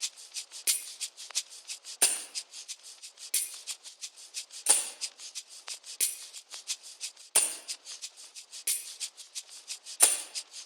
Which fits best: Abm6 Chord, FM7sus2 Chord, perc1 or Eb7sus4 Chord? perc1